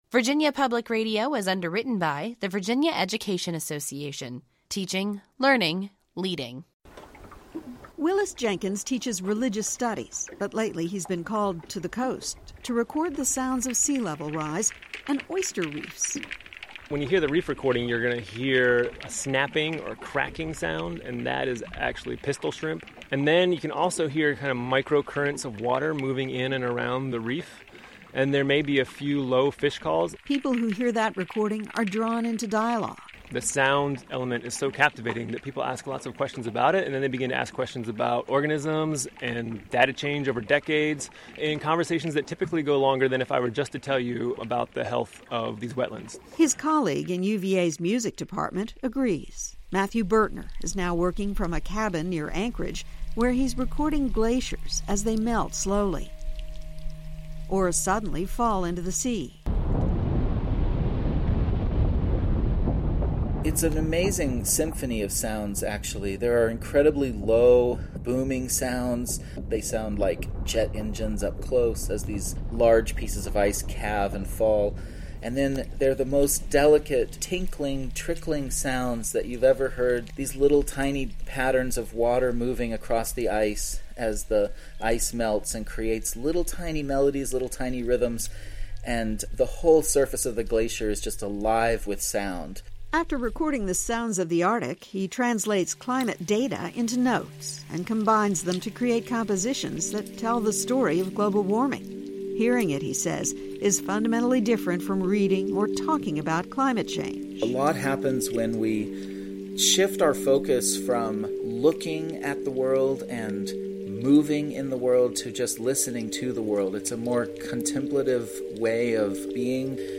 reports from the Eastern Shore.